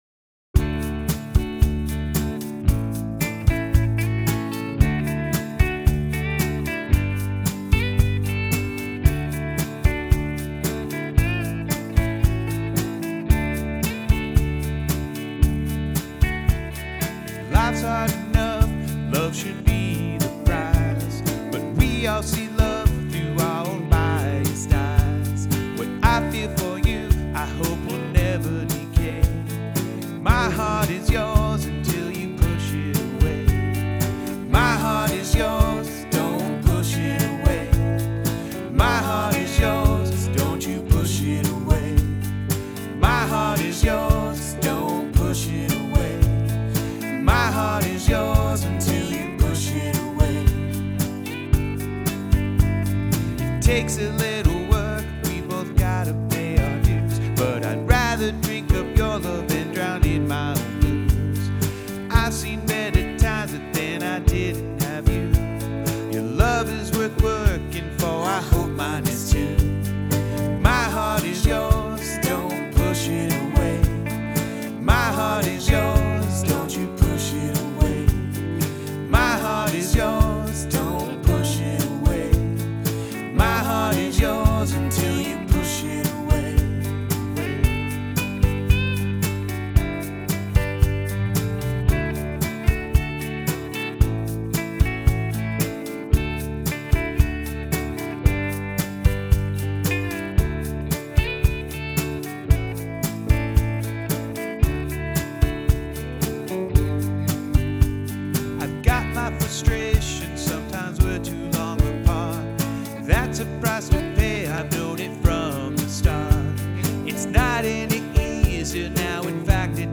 These are our demos — we recorded it all ourselves at home.